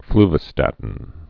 (flvə-stătn)